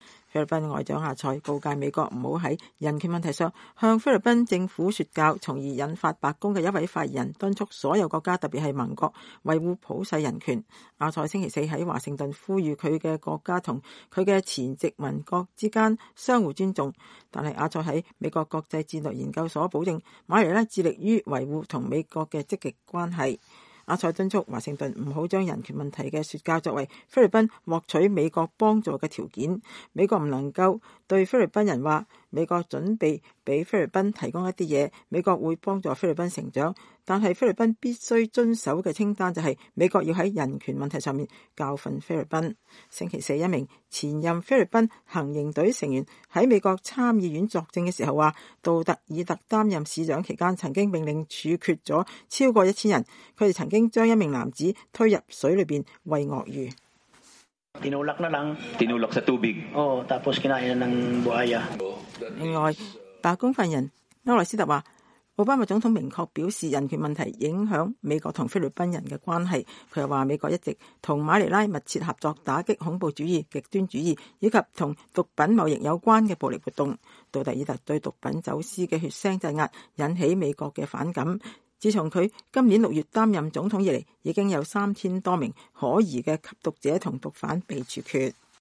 菲律賓外交部長佩費克托雅賽星期四在華盛頓智庫戰略與國際研究中心（CSIS）就菲律賓新政府的外交政策和美菲關係發表演講。